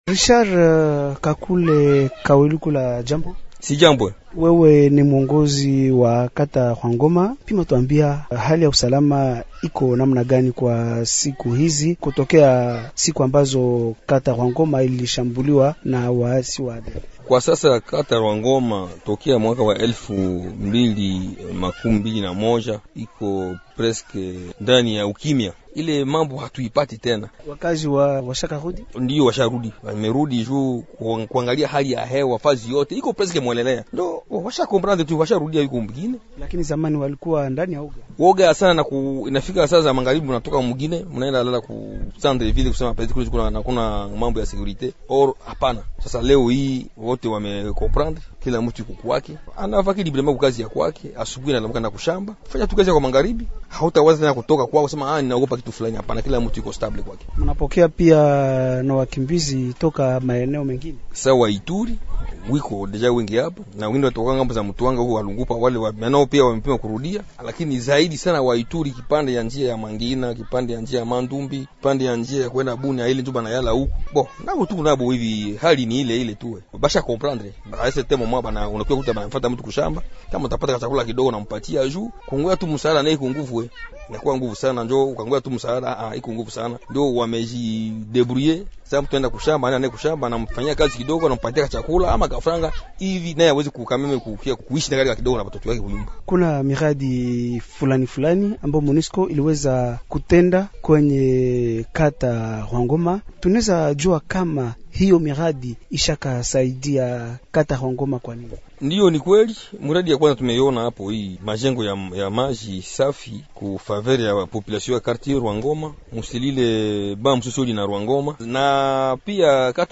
Mgeni wetu ni Richard Kakule Kahwerikula, mkuu wa Kata Rwangoma ya manispa Beu katika mji wa Beni. Kiongozi huyo kimsingi anawasilisha kwetu hali ya sasa katika eneo lake, inayolengwa na mashambulizi ya waasi wa ADF kati ya elfu mbili kumi na sita na elfu mbili makumi mbili na moja, ambapo mamia ya raia walikuwa wameuawa. Pia anazungumzia athari za miradi ya maendeleo ambayo wakazi wa kitongoji chake walinufaika na MONUSCO.